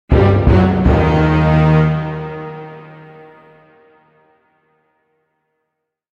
Suspenseful Dum Dum Dum Drum Hit Sound Effect
Description: Suspenseful dum dum dum drum hit sound effect. Add suspense to your films, trailers, and Halloween projects with this cinematic dramatic drum hit sound effect.
This epic timpani sound enhances any cinematic or Halloween scene.
Suspenseful-dum-dum-dum-drum-hit-sound-effect.mp3